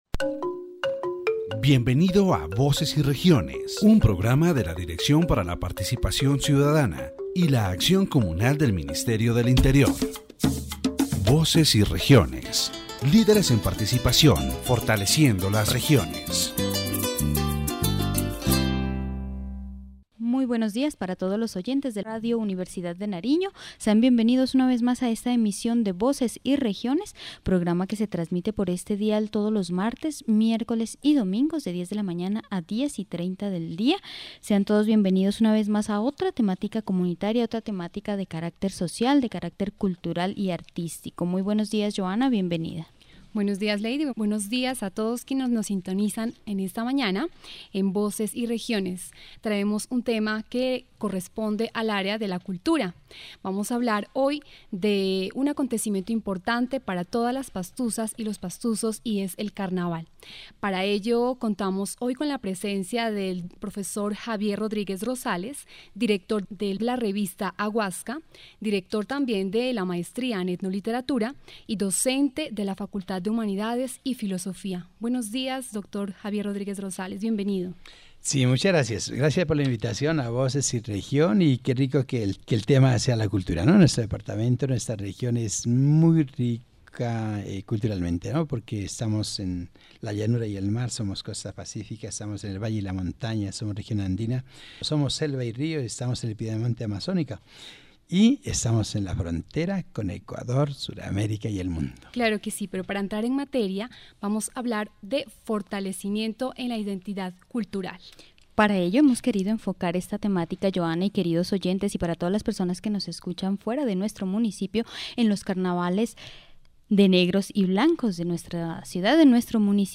The radio program "Voces y Regiones" dedicated a special broadcast to the Carnival of Blacks and Whites of Pasto, exploring in depth this festivity that transcends local borders and has been consolidated as a cultural heritage of humanity.